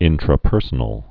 (ĭntrə-pûrsə-nəl)